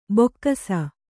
♪ bokkaa